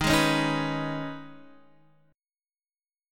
Eb7sus4#5 chord